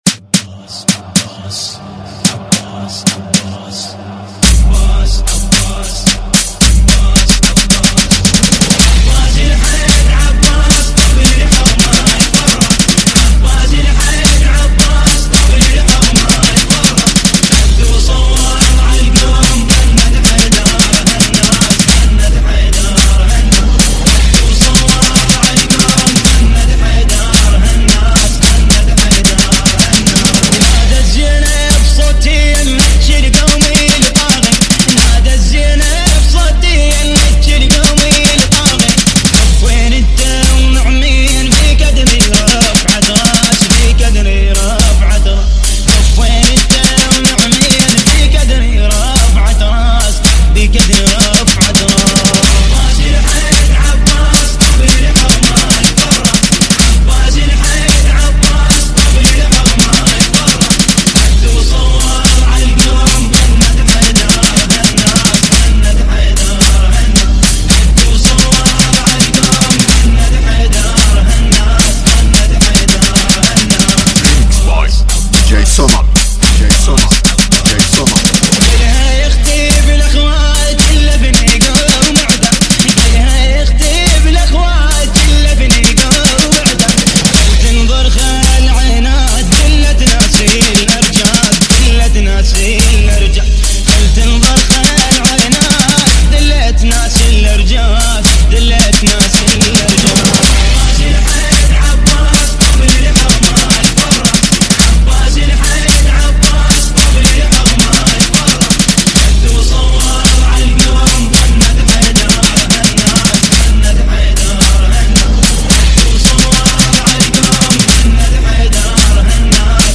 دانلود مداحی ویژه برای محرم ۱۴۰۳